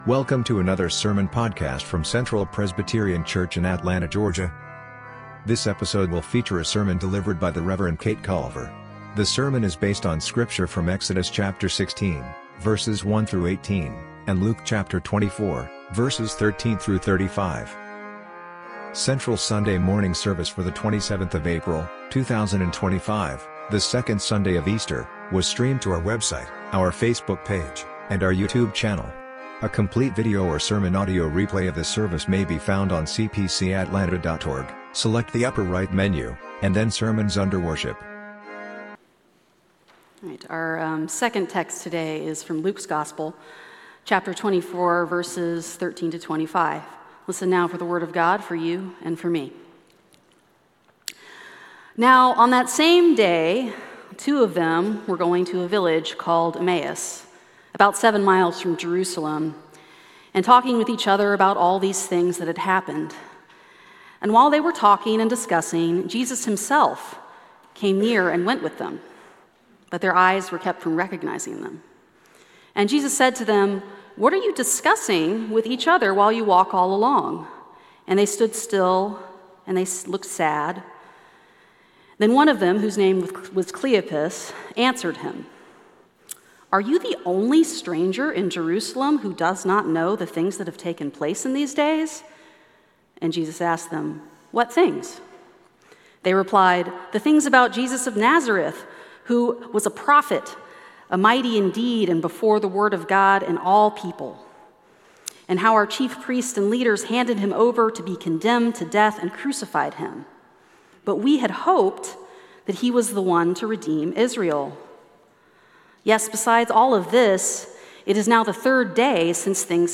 Sermon Audio: